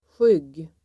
Uttal: [sjyg:]